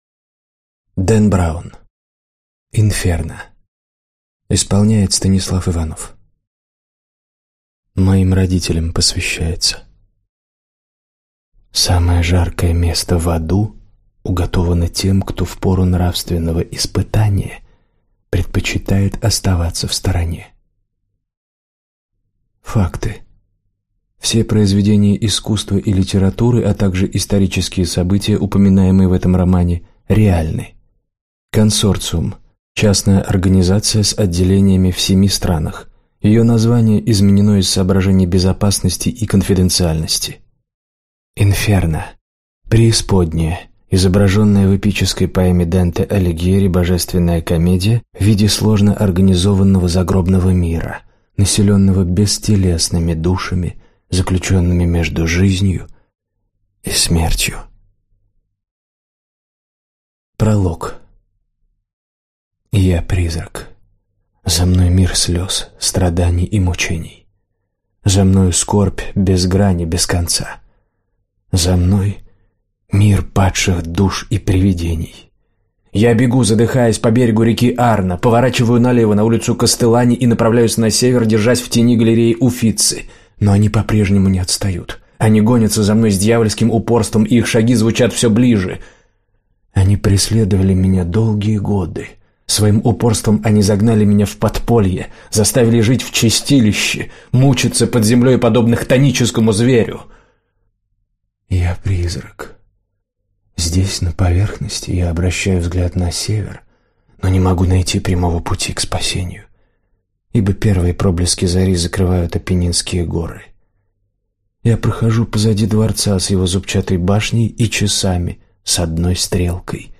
Аудиокнига Инферно - купить, скачать и слушать онлайн | КнигоПоиск